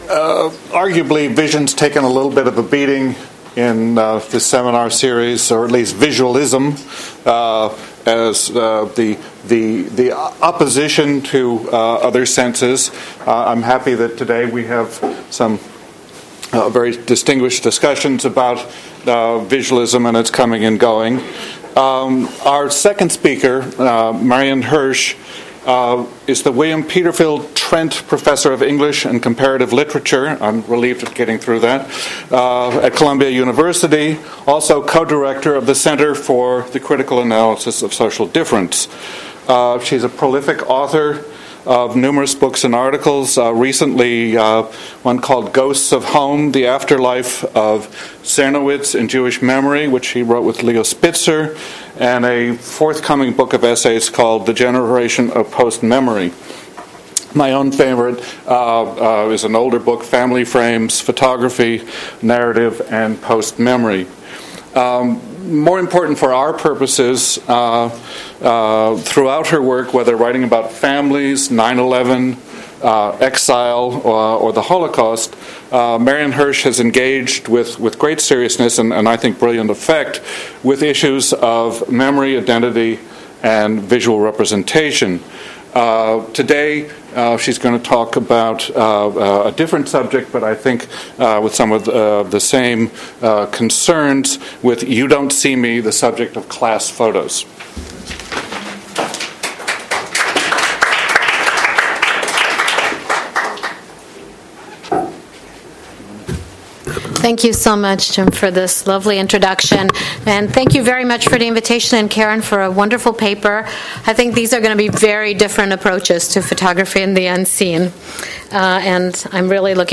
Each session will feature research presentations by two invited speakers, followed by discussion led by one or two local scholars.